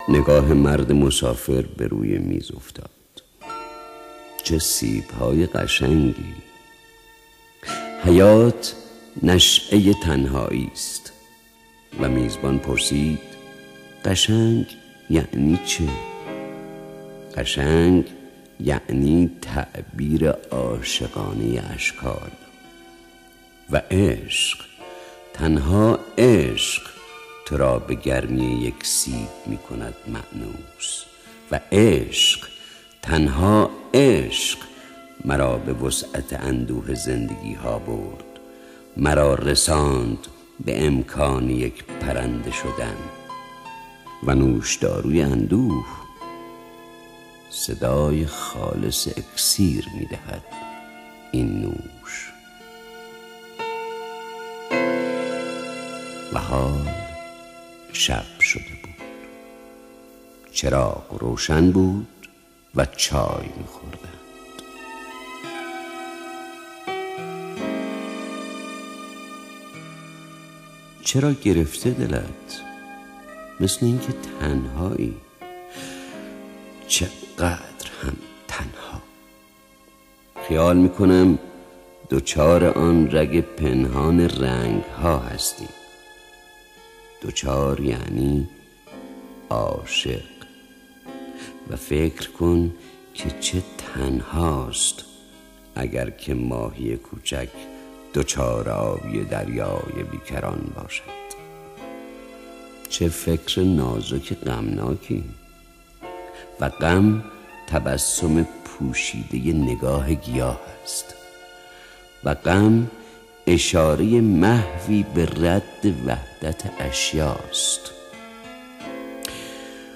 دانلود دکلمه مسافر2 با صدای خسرو شکیبایی با متن دکلمه
گوینده :   [خسـرو شکیبایی]